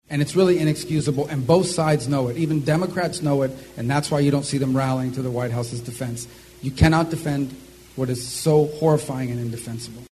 Speaking at an Iowa GOP fundraiser at the Mason City Municipal Airport Monday night, Rubio said the pullout plan was based on assumptions that were not real.